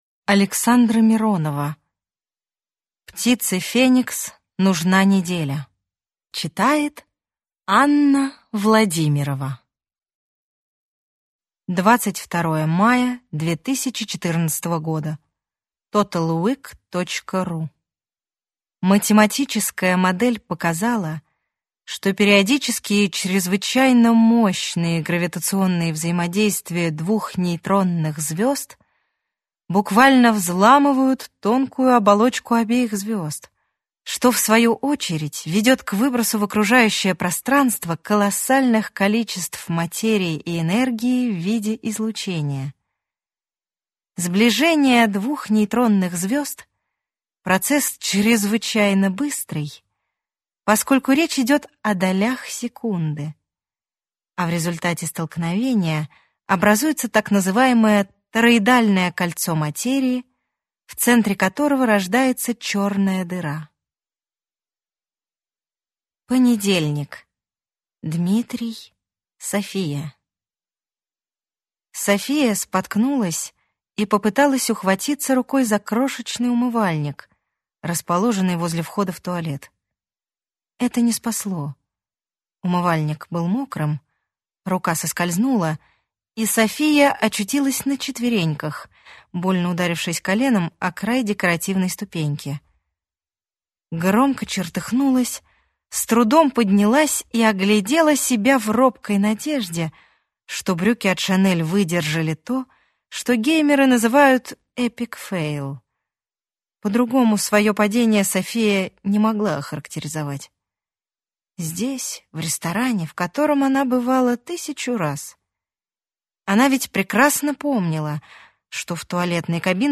Аудиокнига Птице Феникс нужна неделя | Библиотека аудиокниг